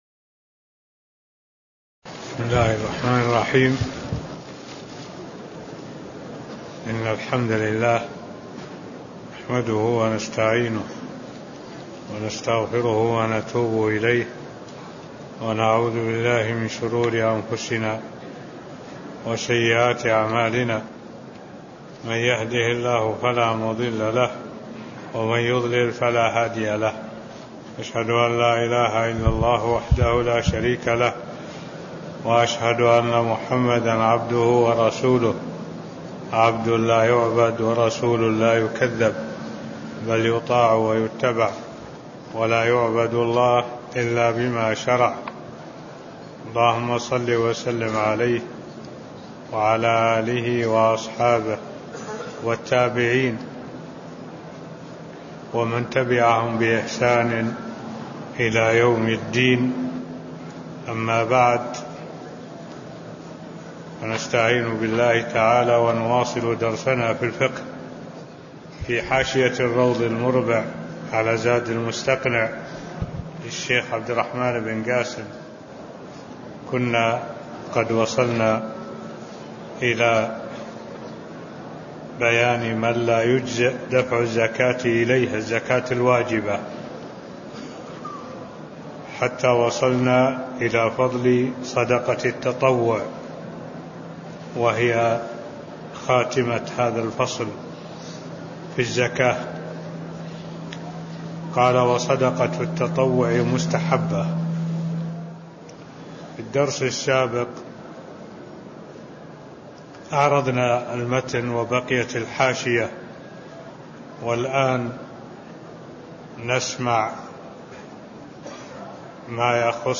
تاريخ النشر ١٢ جمادى الآخرة ١٤٢٧ هـ المكان: المسجد النبوي الشيخ: معالي الشيخ الدكتور صالح بن عبد الله العبود معالي الشيخ الدكتور صالح بن عبد الله العبود فضل صدقة التطوع (011)